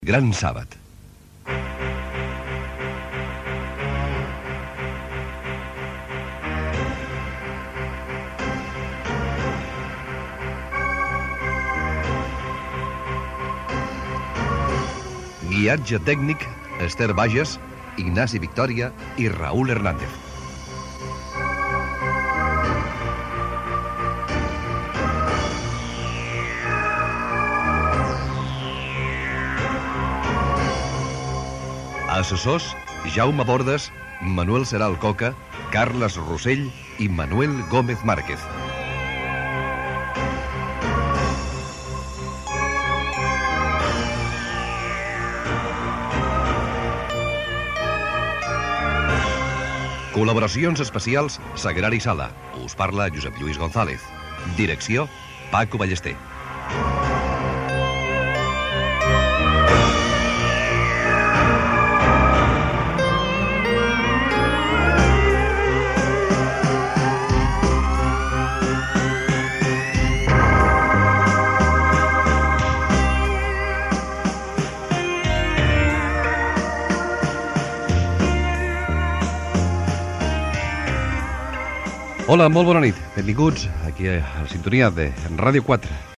Presentació amb els noms de l'equip.
Divulgació
FM